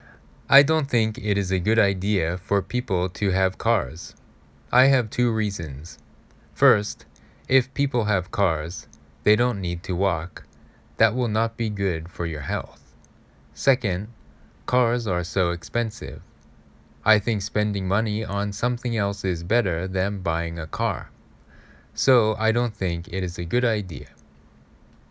英会話ロングアイランドカフェの英作文会話 3/8 - 英検準２級・シャドーイング用音声 ↑↑↑ レッスン後には毎回添削文と音声をお送りしてます。 サンプルとして音声リンクも入れました♪ 自分の英作文だから記憶に残りやすいのです。